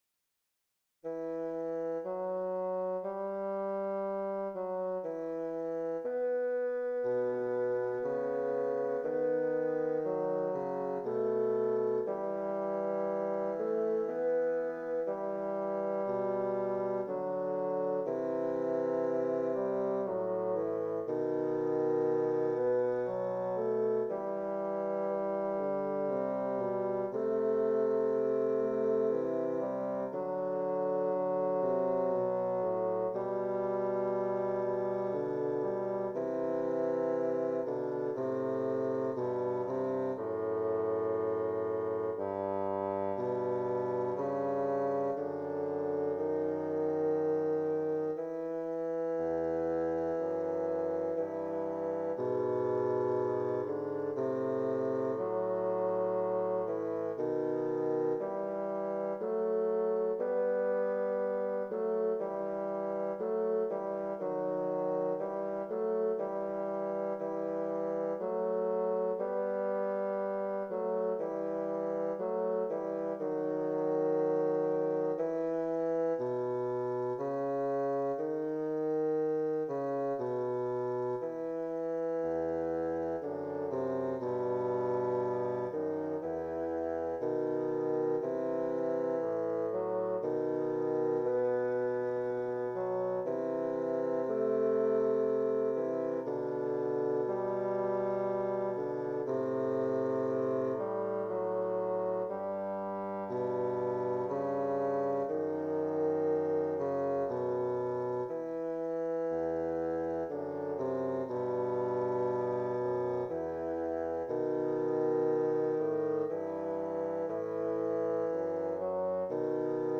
Intermediate bassoon duet
Instrumentation: bassoon duet
tags: bassoon music